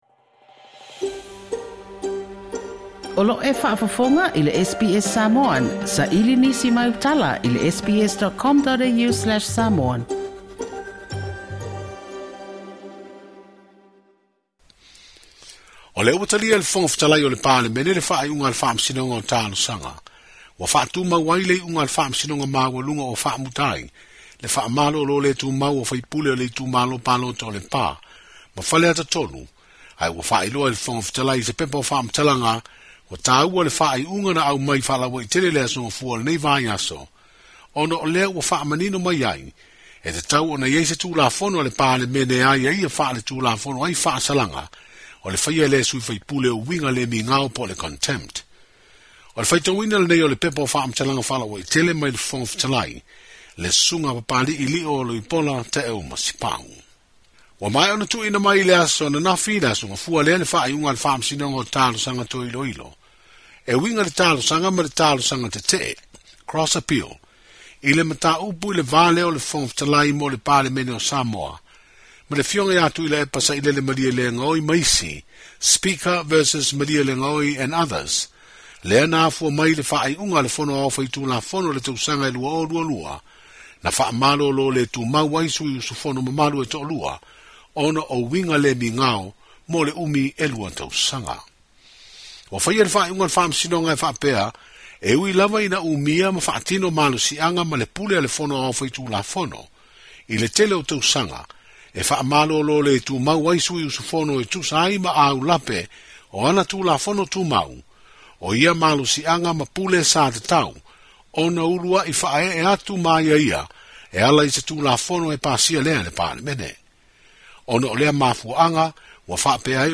I le ripoti lenei